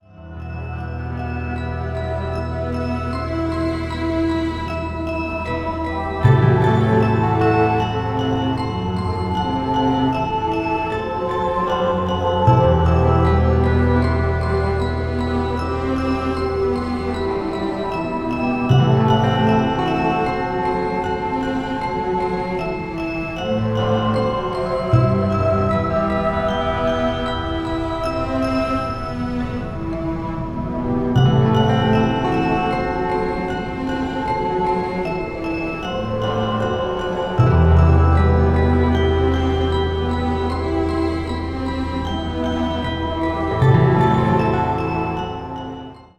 8:58 39 bpm
12-string LucyTuned guitar